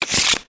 shuffle_sound_effect.wav